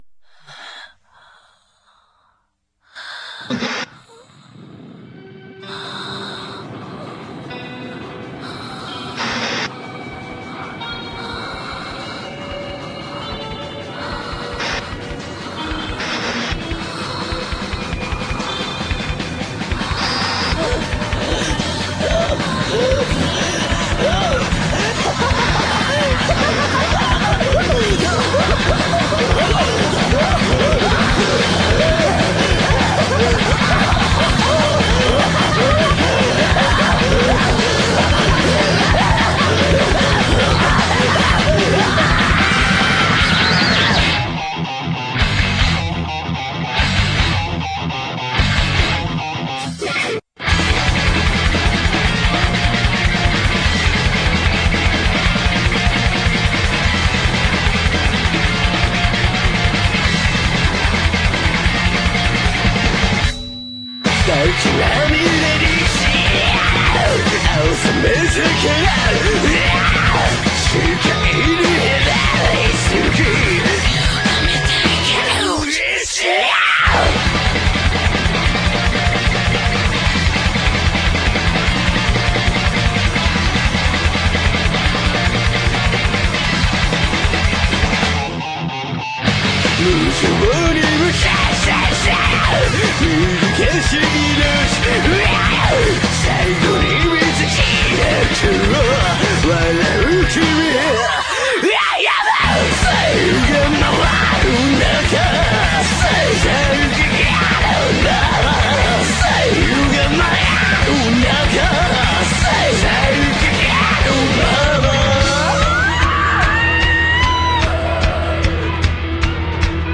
lots of heavy breathing and gasping